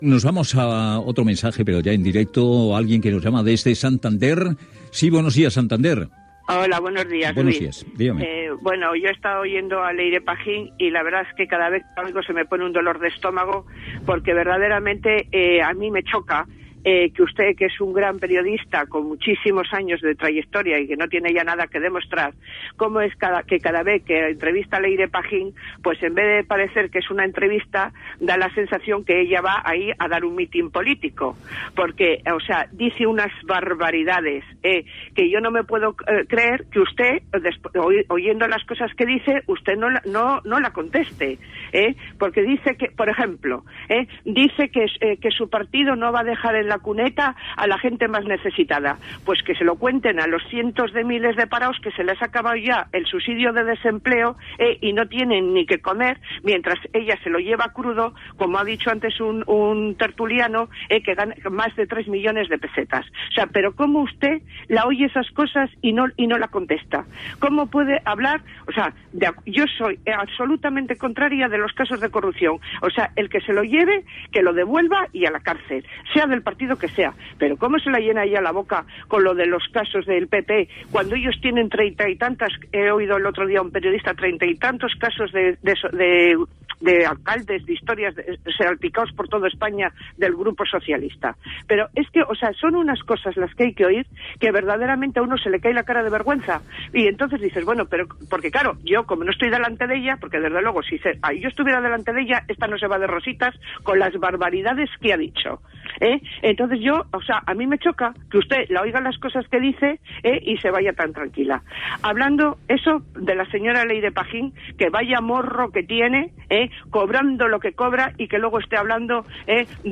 Crítica d'una oient de Santander a Luis del Olmo per una entrevista que va fer a la política Leire Pajín.
Info-entreteniment